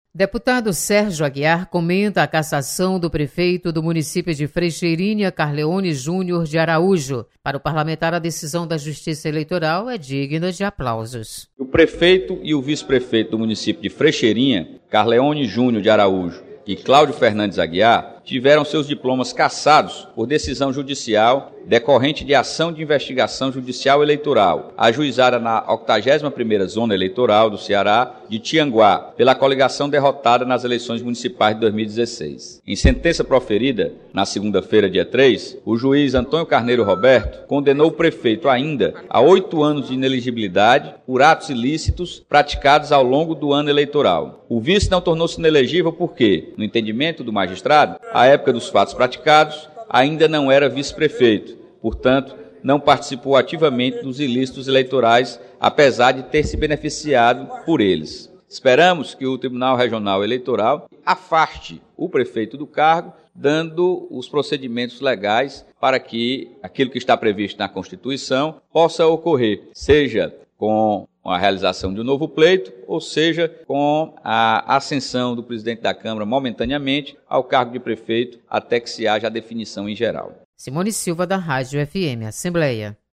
Cassação de prefeito é comentada no Plenário 13 de Maio. Repórter